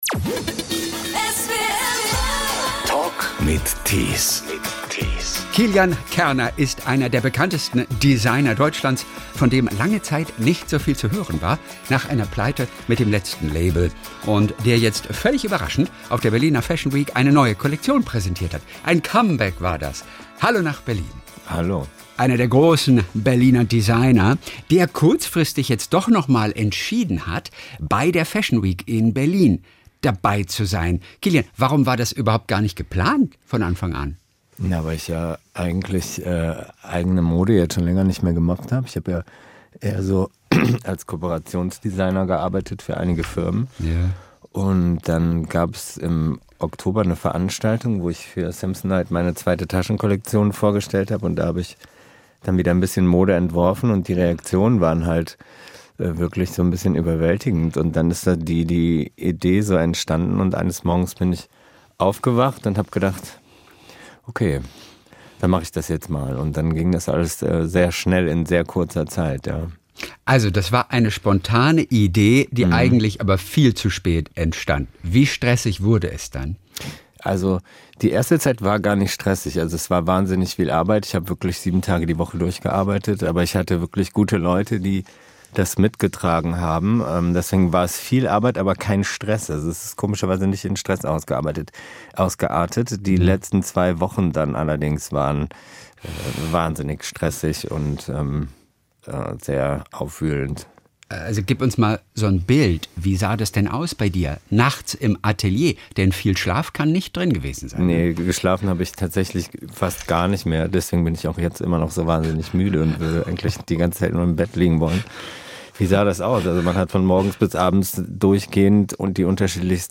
Der Talk in SWR 3